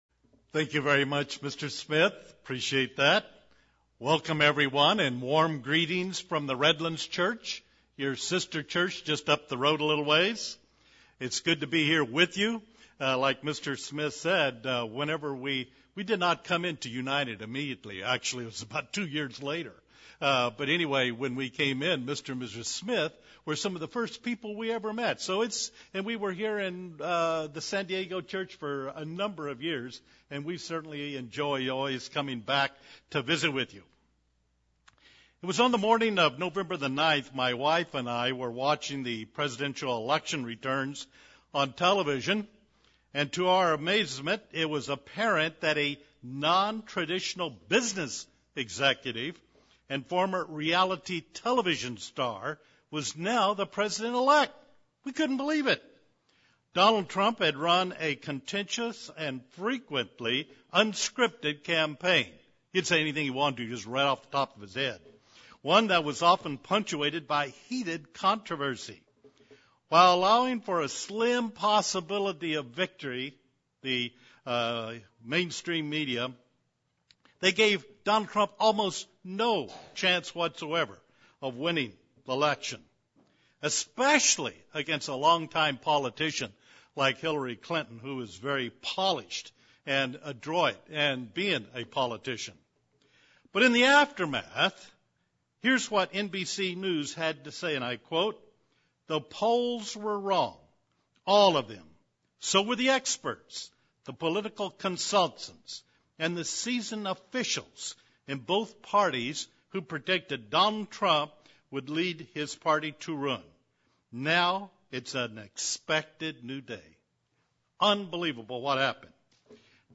Sermons
Given in San Diego, CA